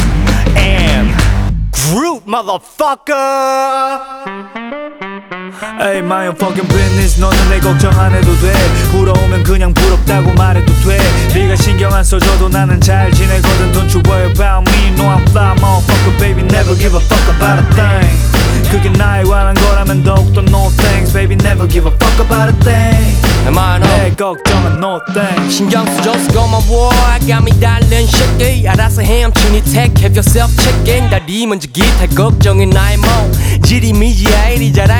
Жанр: Хип-Хоп / Рэп / Поп музыка